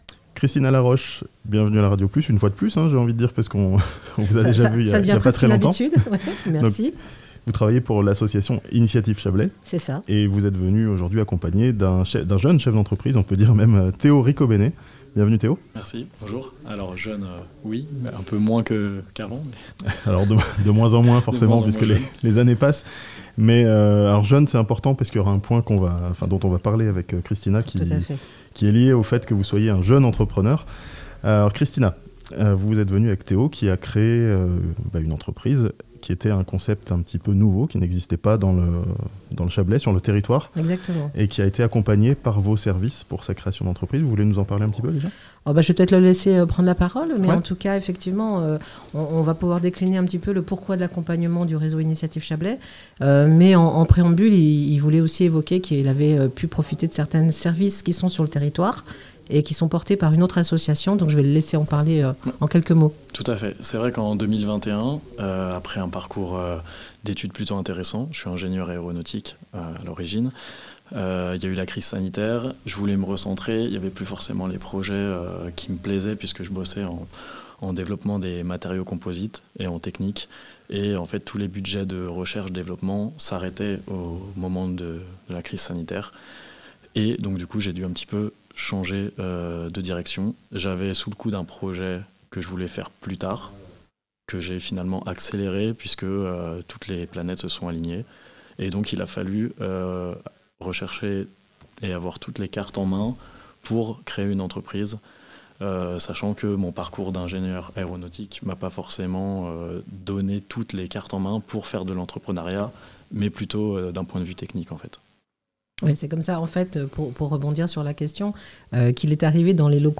Prendre soin des voitures de luxe de ses clients, l'aventure entrepreneuriale d'un jeune Chablaisien (interview)